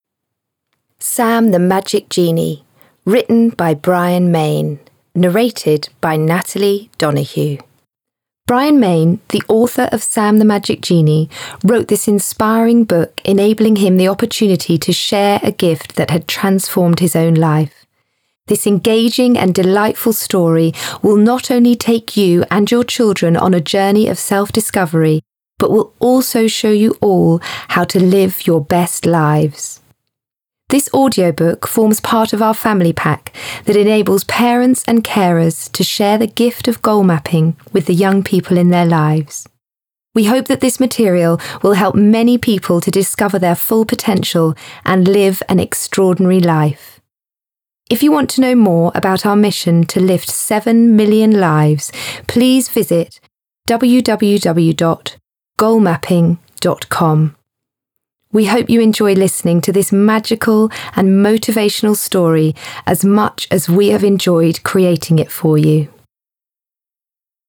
Sjanger: Children.